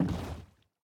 paddle_land6.ogg